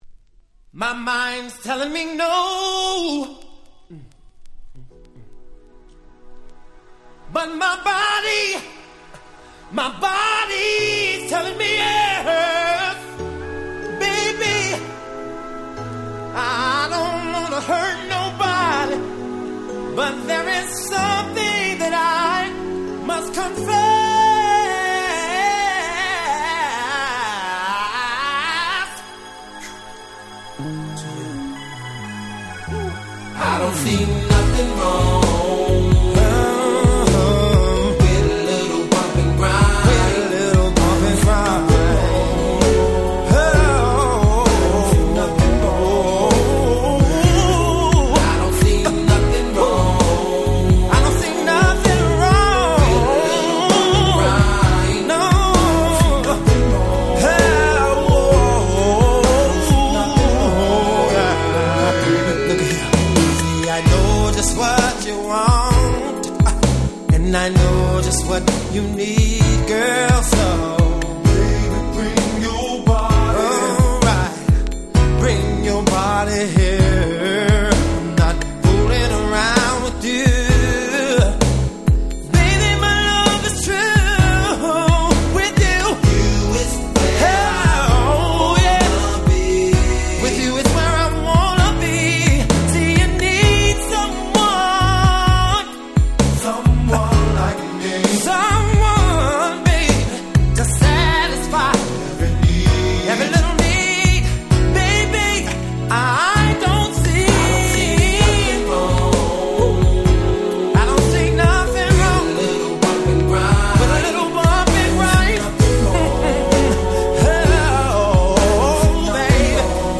94' Slow Jam Classic.